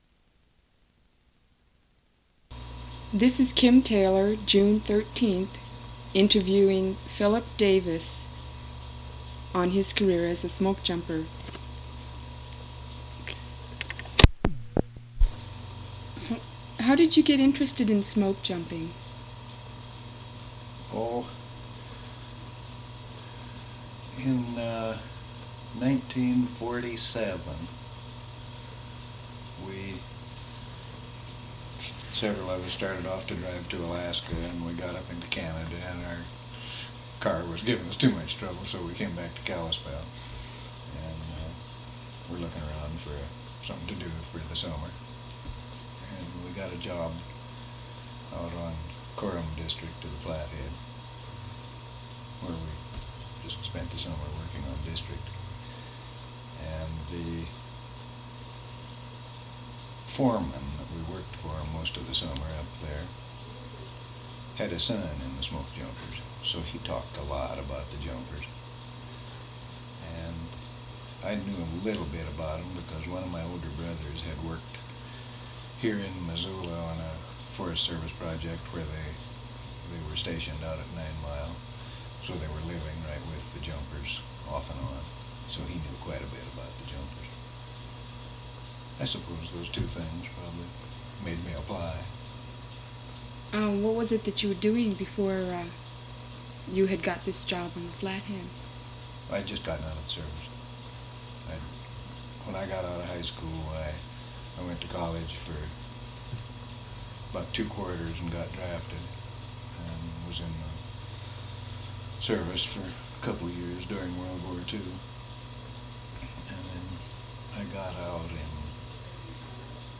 Document Type Oral History
Original Format 1 sound cassette (65 min.) : analog